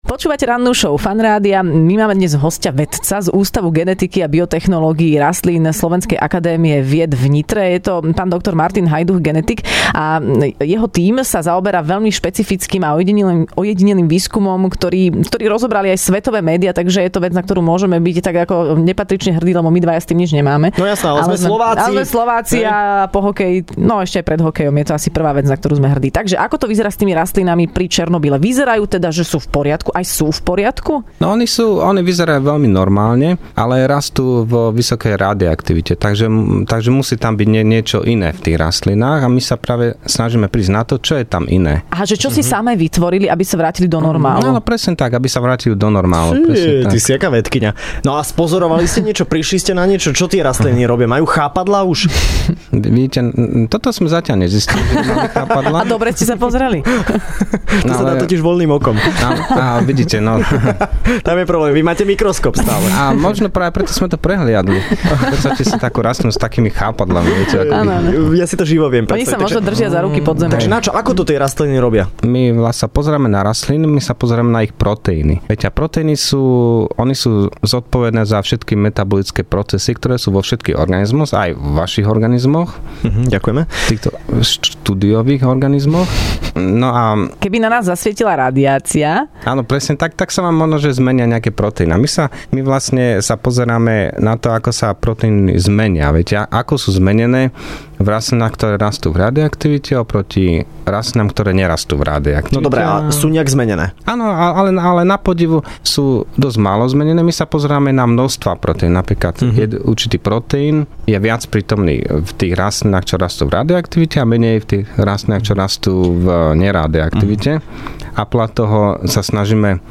Hosťom v Rannej šou bol genetik